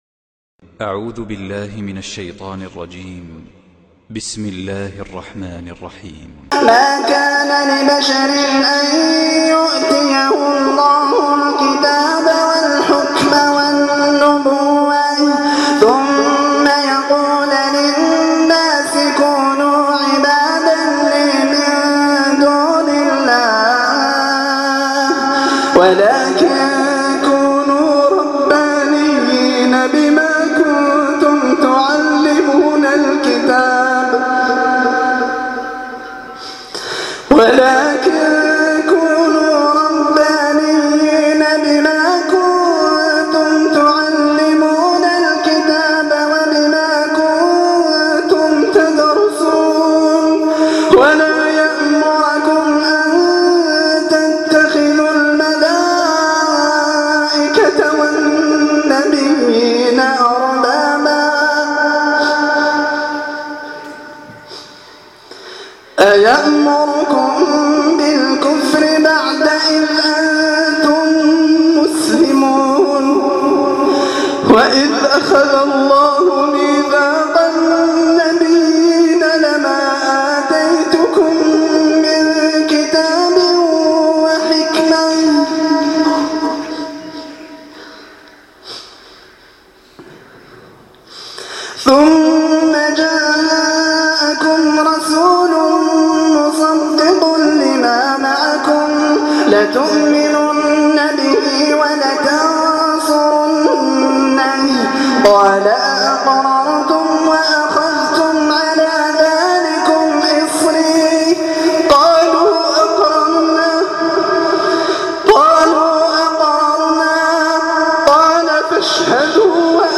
من صلاة التراويح لم يتمالك نفسه وبكى تلاوة مؤثرة